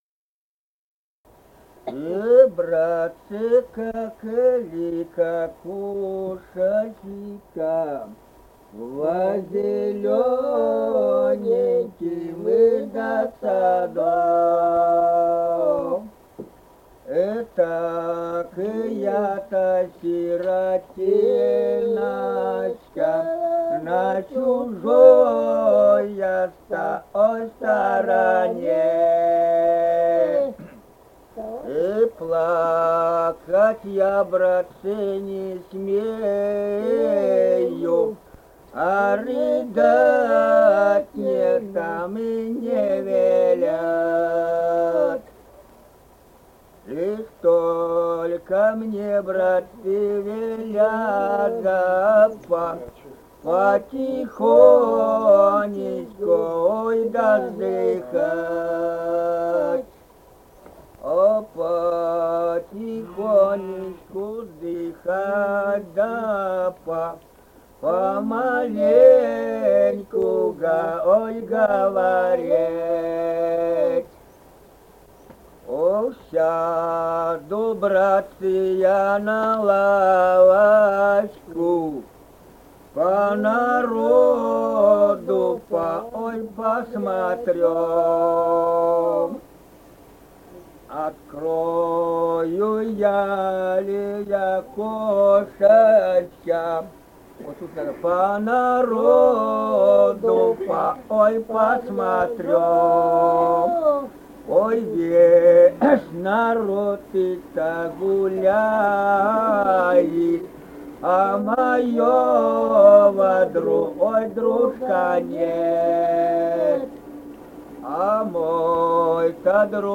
Республика Казахстан, Восточно-Казахстанская обл., Катон-Карагайский р-н, с. Коробиха, июль 1978.